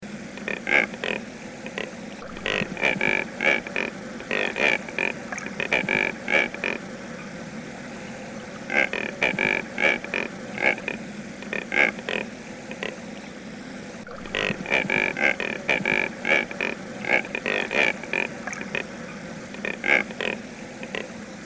Cormorán Yeco (Phalacrocorax brasilianus)
vocalización: